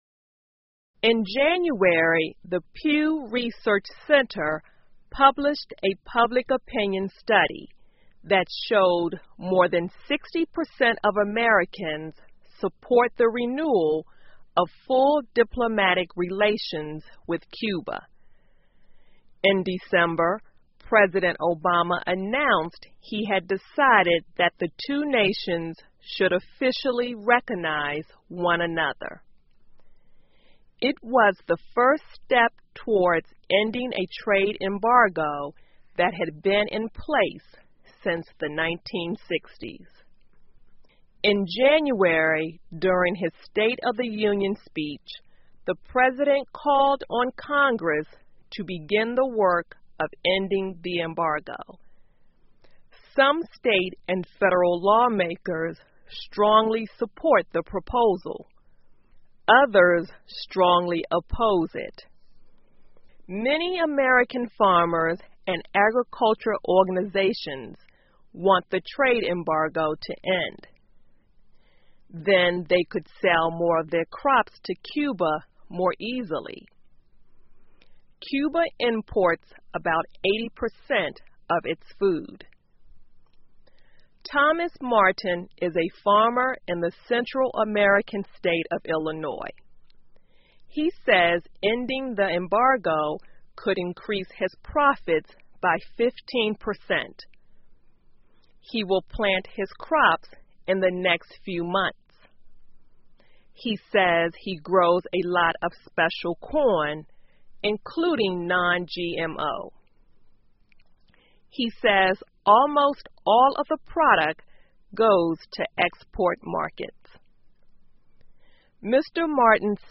VOA慢速英语2015 巴基斯坦逮捕拒绝为孩子接种脊髓灰质炎疫苗的父母 听力文件下载—在线英语听力室